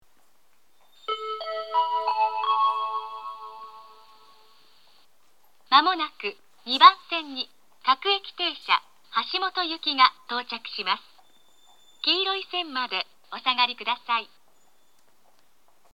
東海道型(女性)
接近放送